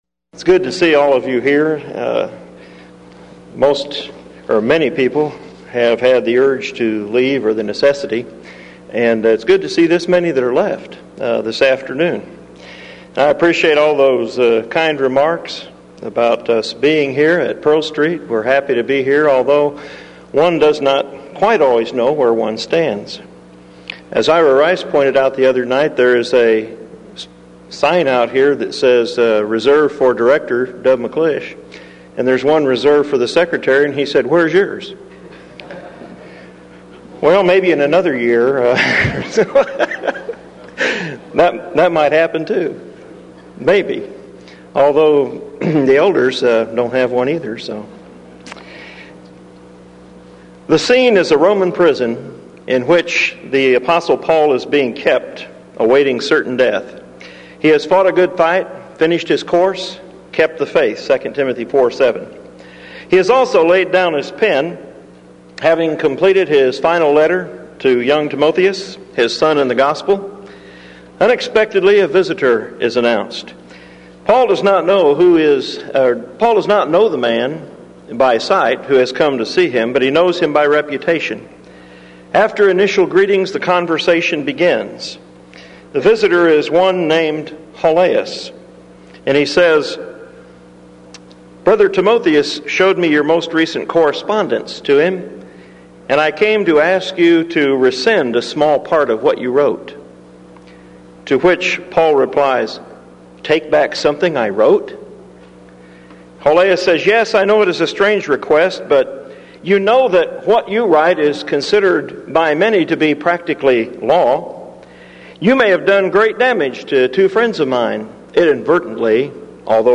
Title: DISCUSSION FORUM: Is Open Division in the Church over Liberalism Inevitable?
Event: 1995 Denton Lectures